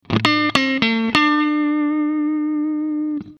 These simulation are optimal for the overall tone, really near the original also for the sustain.
In each file I recorded the same phrase with a looper: first with the Original MXR DynaComp inserted at the beginning of the chain and a second recording with the Kemper Stomps.
Some Audio tests (first the Original MXR and second the KPA):
DEMO-DYNACOMP-KPA-3.mp3